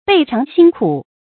备尝辛苦 bèi cháng xīn kǔ 成语解释 备：尽、全。